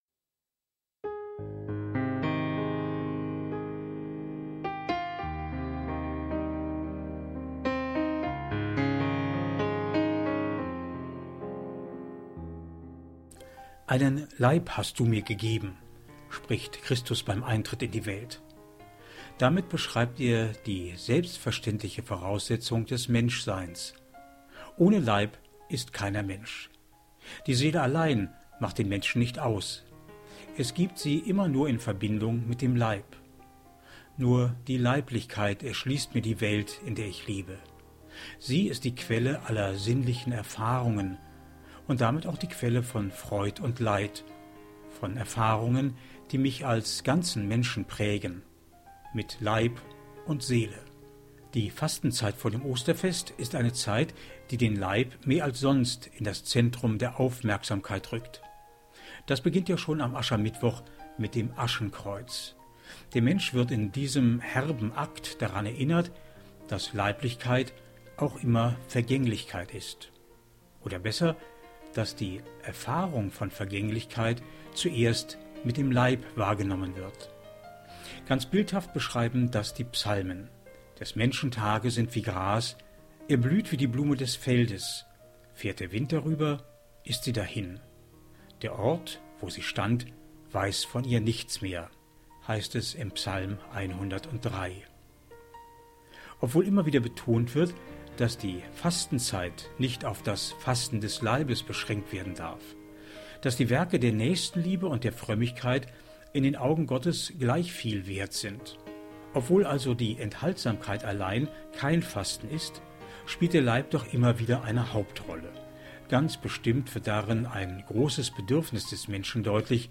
MEDITATION